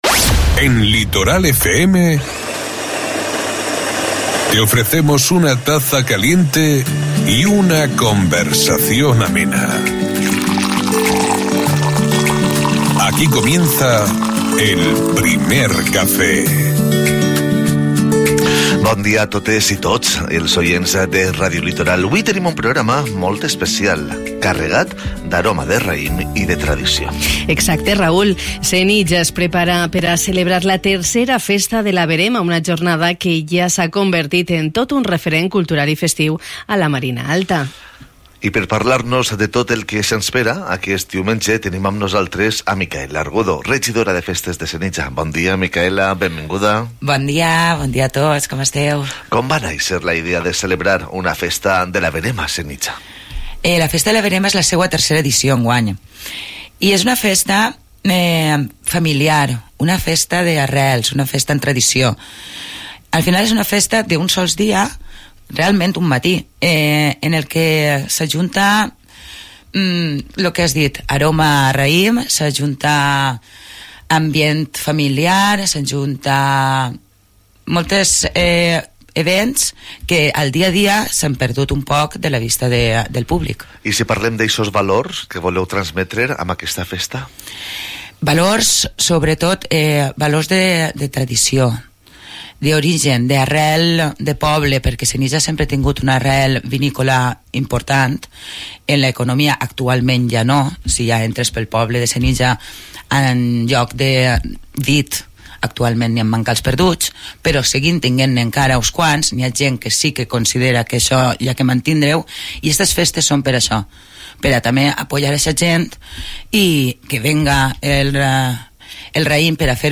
En el Primer Café de Radio Litoral hemos conversado esta mañana con Micaela Argudo, concejala del Ayuntamiento de Senija, sobre esta convocatoria que nos conecta con nuestras raíces y tradiciones agrícolas.